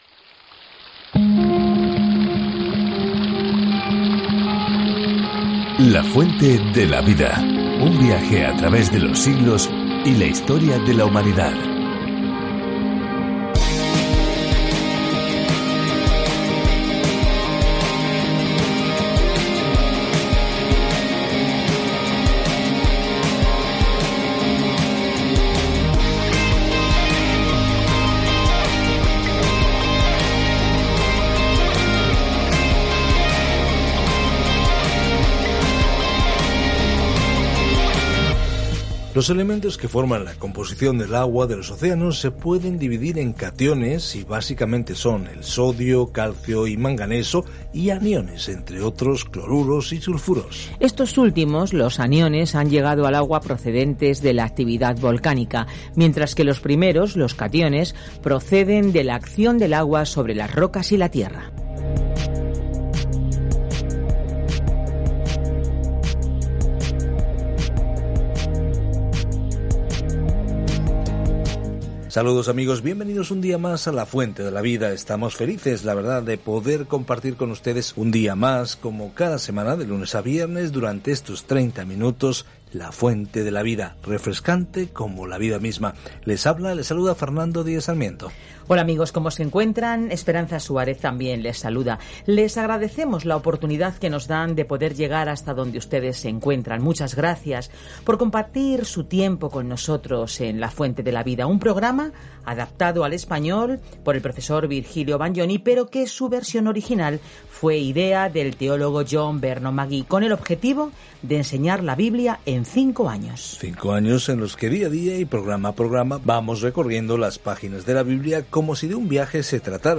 Escritura ZACARÍAS 12:6-14 Día 29 Iniciar plan Día 31 Acerca de este Plan El profeta Zacarías comparte visiones de las promesas de Dios para dar a las personas una esperanza en el futuro y las insta a regresar a Dios. Viaja diariamente a través de Zacarías mientras escuchas el estudio en audio y lees versículos seleccionados de la palabra de Dios.